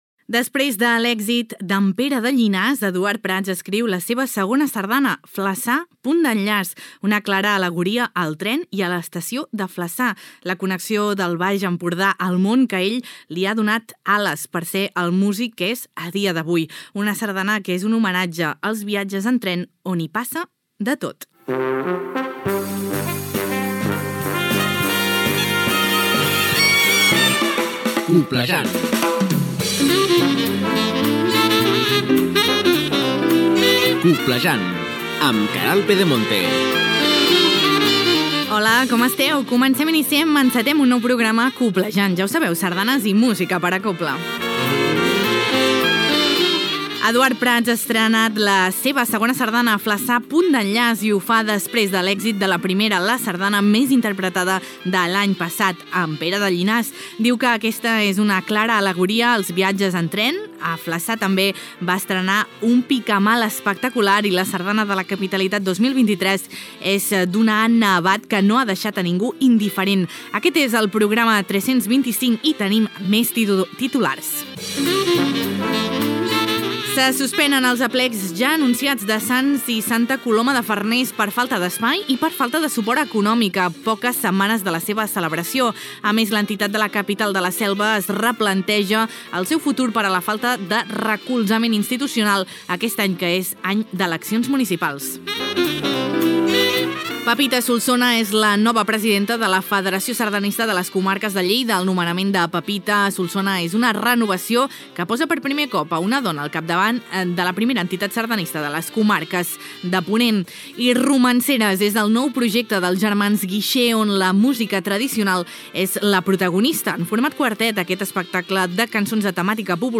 Nova sardana, careta del programa, resum informatiu de l'actualitat sardanista, estrena d'una sardana dedicada al tren de Flassà d'Eduard Prats.